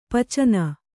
♪ pacana